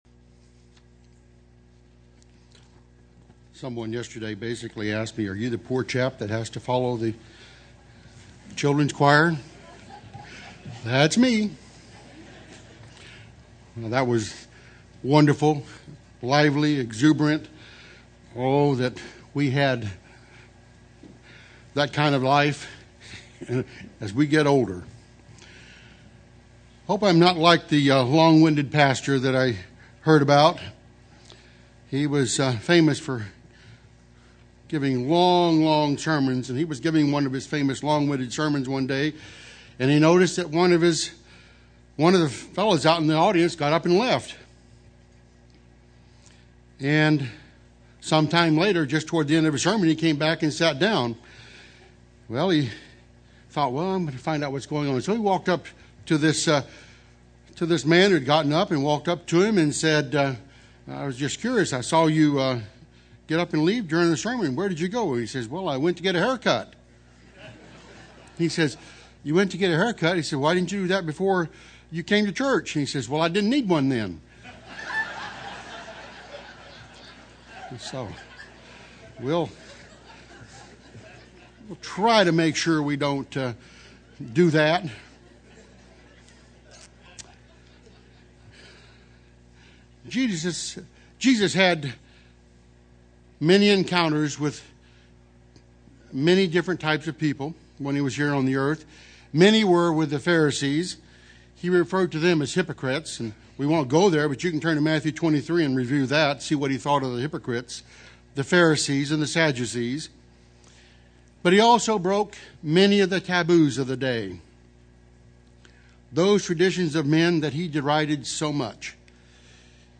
This sermon was given at the Branson, Missouri 2011 Feast site.